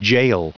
Prononciation du mot gaol en anglais (fichier audio)
Prononciation du mot : gaol